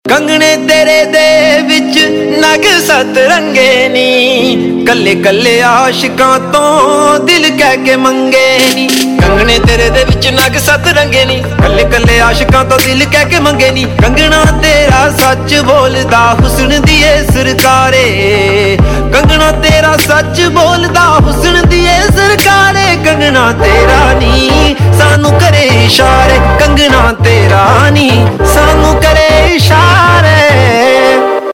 Punjabi Mp3 Tone